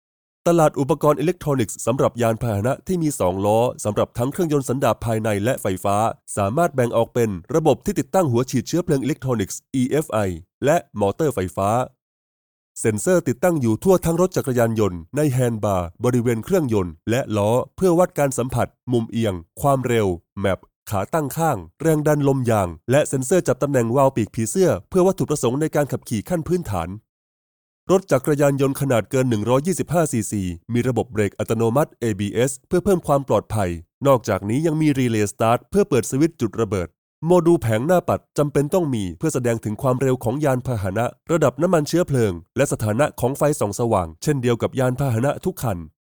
Vídeos explicativos